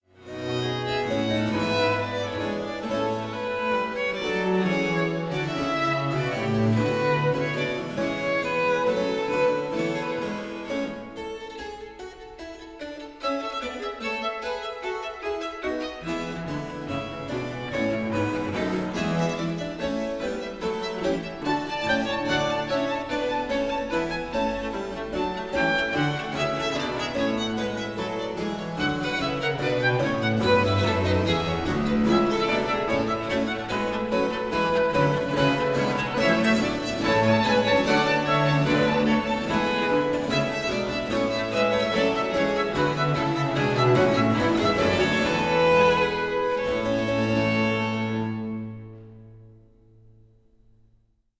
violin
bass viol
theorbo
chamber organ, harpsichord